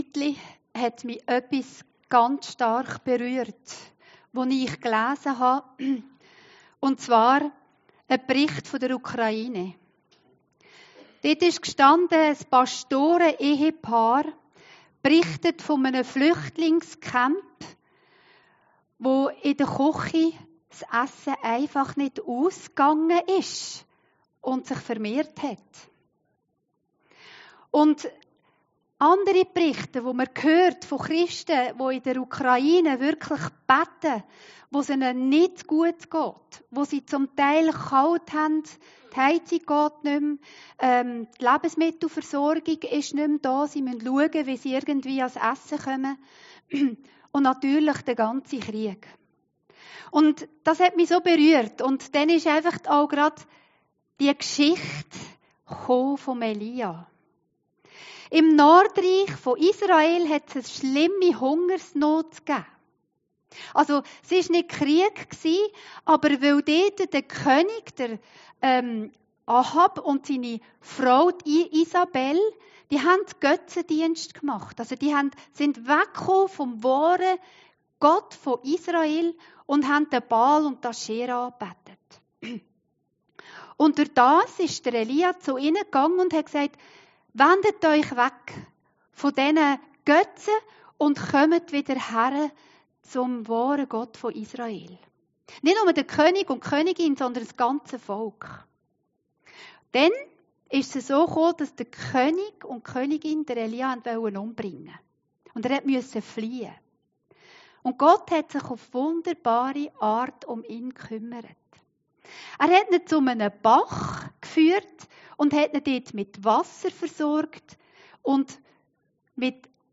Predigten Heilsarmee Aargau Süd – GOTT VERSORGT DICH!